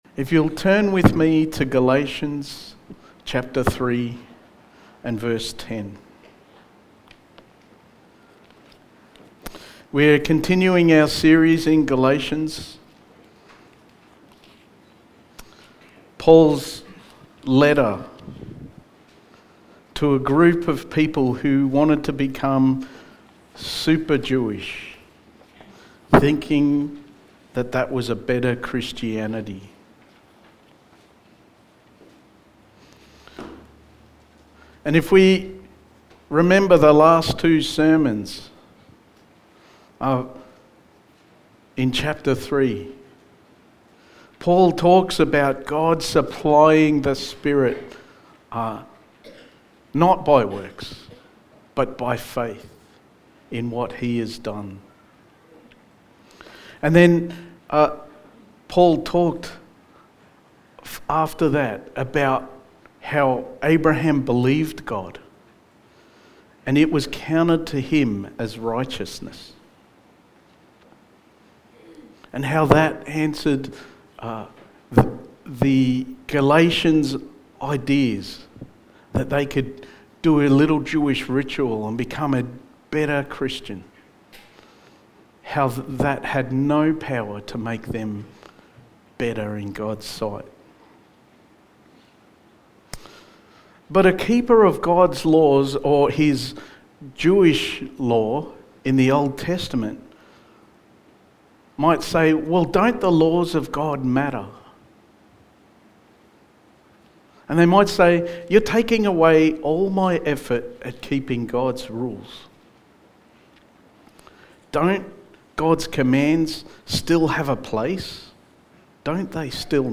Sermon
Galatians 3:10-14 Service Type: Sunday Morning Sermon 8 « Shortcutting Faith